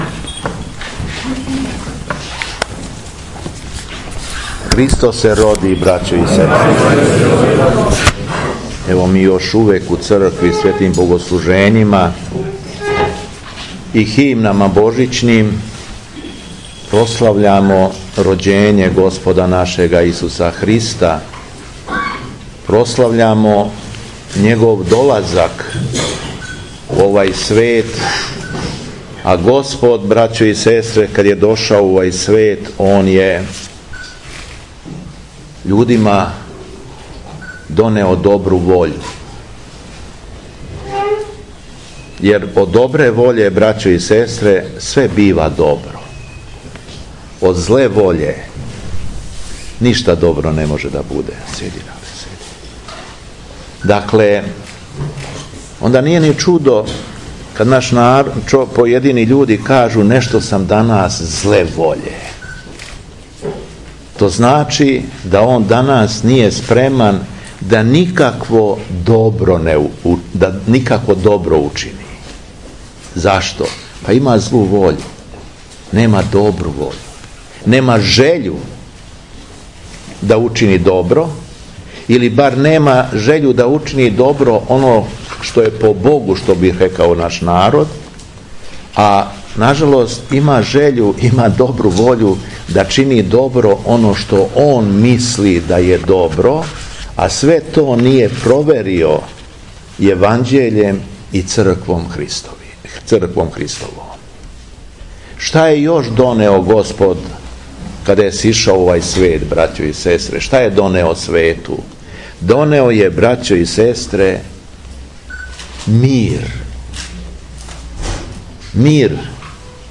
У четвртак 12. јануара 2023. године, Његово преосвештенство Епископ шумадијски господин Јован служио је Свету архијерејску Литургију у Старој цркви у...
Беседа Његовог Преосвештенства Епископа шумадијског г. Јована